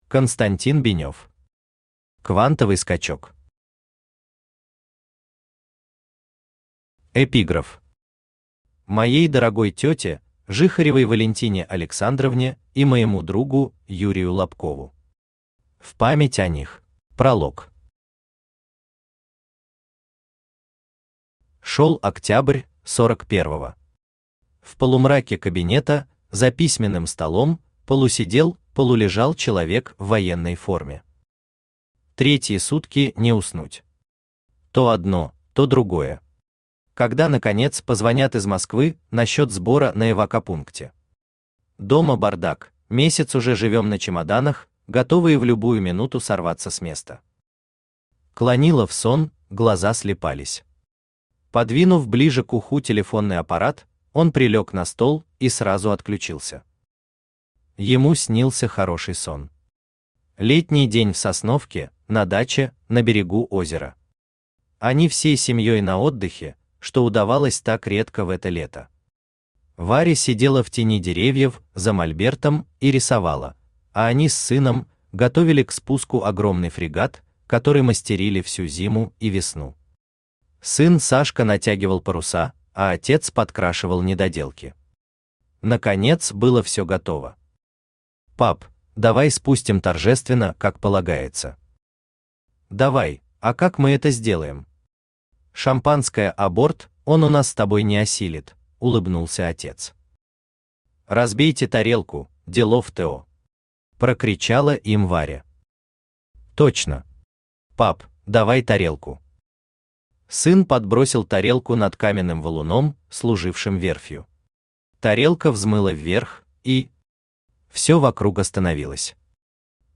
Аудиокнига Квантовый Скачок | Библиотека аудиокниг